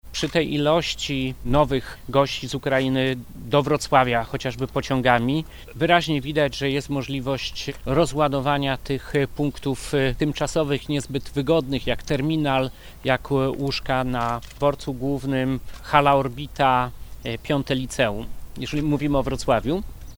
– Widzimy sukcesy związane z dyslokacją – mówi Jarosław Obremski – wojewoda dolnośląski.